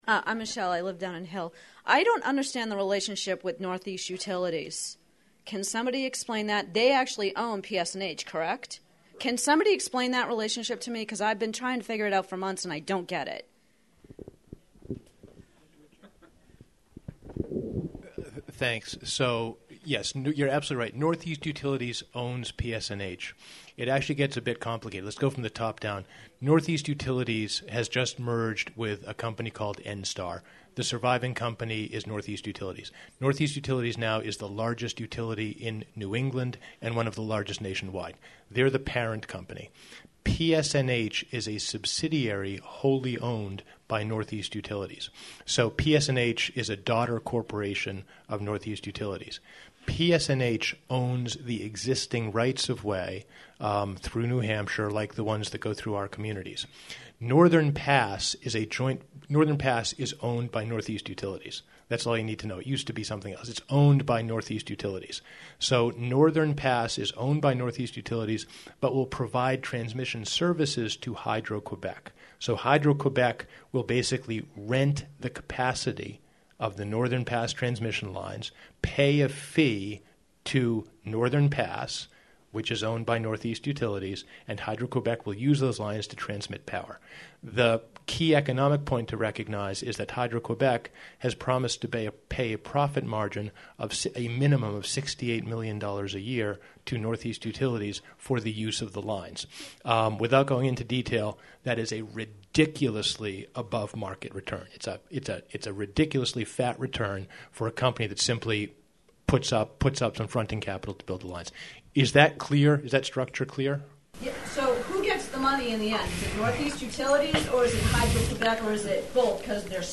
About 100 people attended a meeting of concerned citizens at the Sugar Hill Town Hall to hear from opponents to the Northern Pass project in a panel discussion.